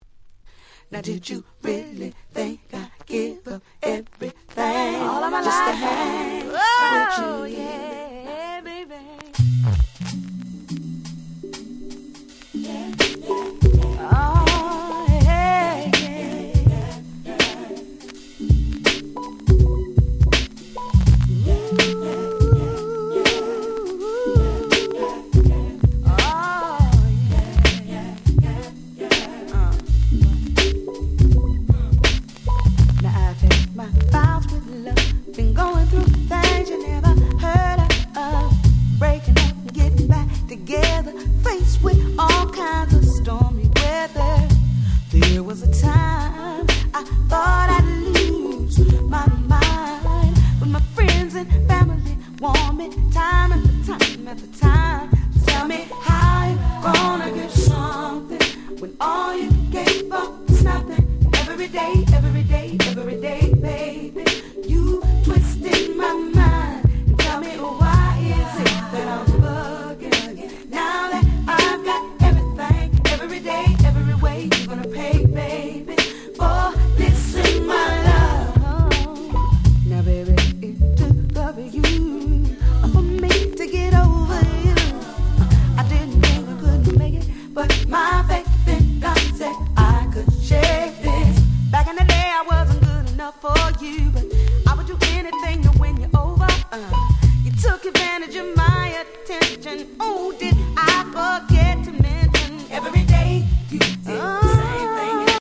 97' Nice Neo Soul / R&B !!
土臭くもGroovyな最高の仕上がり！！